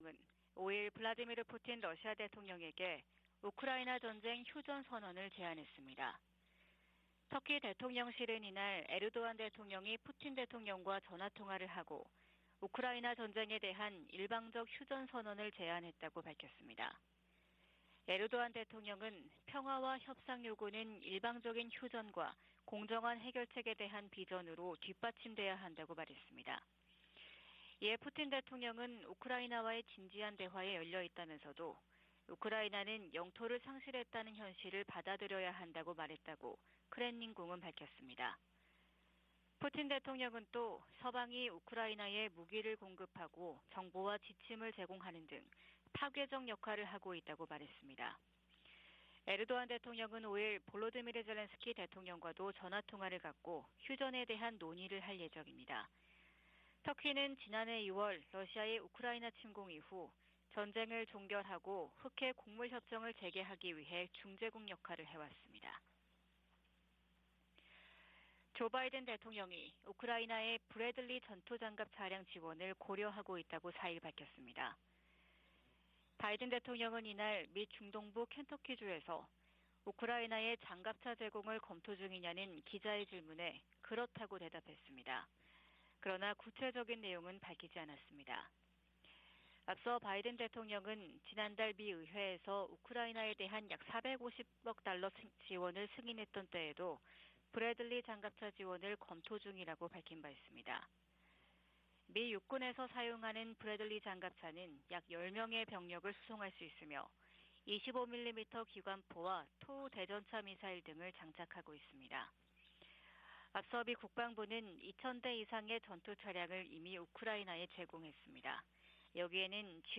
VOA 한국어 '출발 뉴스 쇼', 2023년 1월 6일 방송입니다. 한국 국가정보원은 북한 무인기의 용산 대통령실 촬영 가능성을 배제할 수 없다고 밝혔습니다. 미국은 북한 정권의 핵 무력 추구를 면밀히 주시하며 미한일 군사협력 강화를 계속 모색할 것이라고 백악관 고위관리가 밝혔습니다.